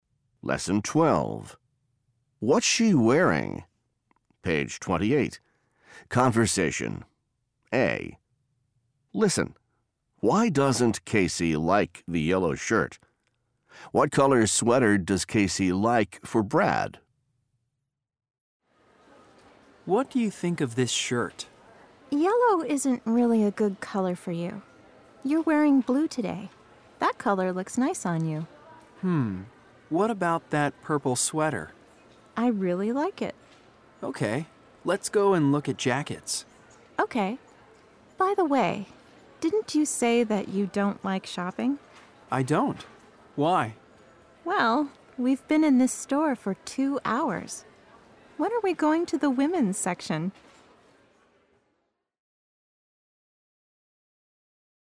Part 2 - Conversation
فایل صوتی مکالمه بدون متن